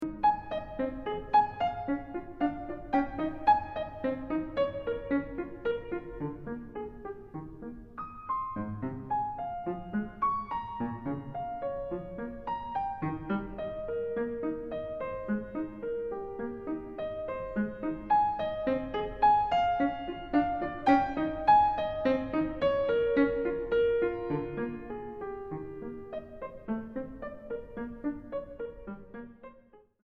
Chiaro e Deciso 1:02